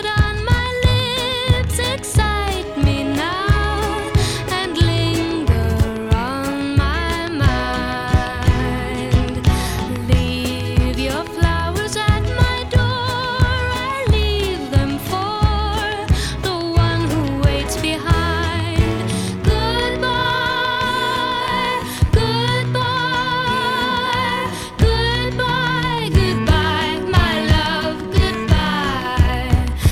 Жанр: Поп музыка / Рок / Фолк